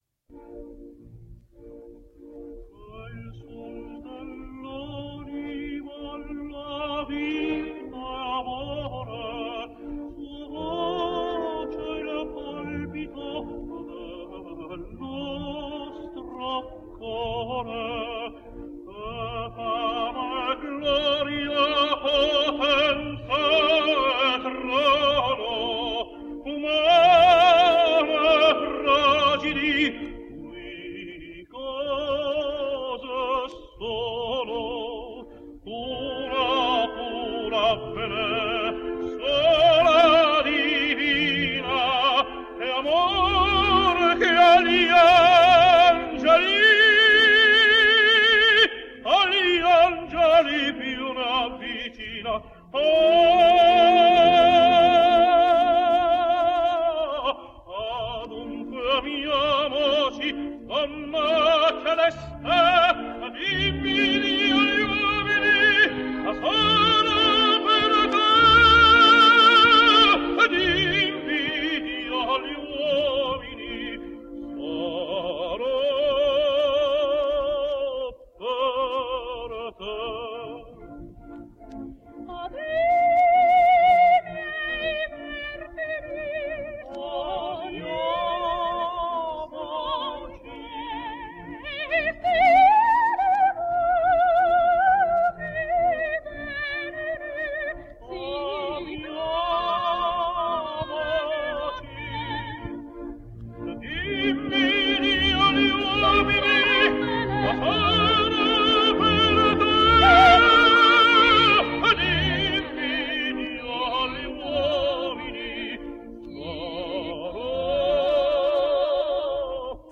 Italian Tenor.
He could spin out a sustained note until it became a mere thread of tone.
So, here are two samples where he is joined by that fine Italian coloratura soprano Lena Pagliughi.